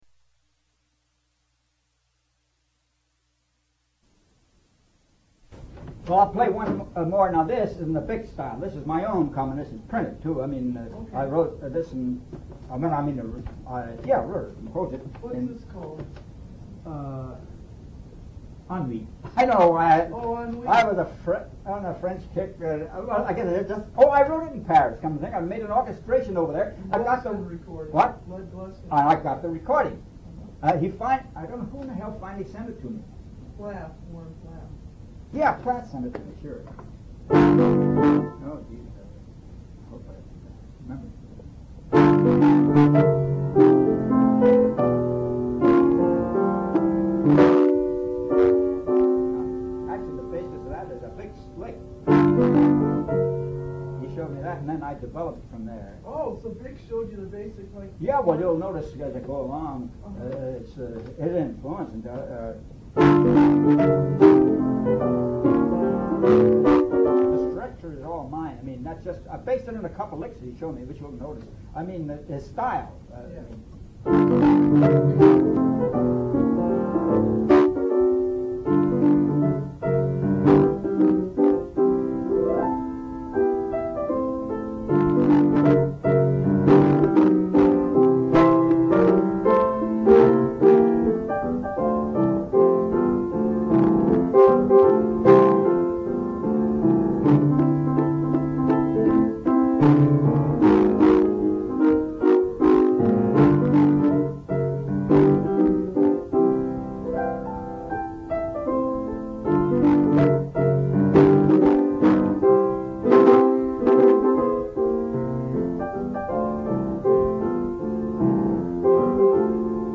slow-fox
piano
a copy of the tape of the interview is available here.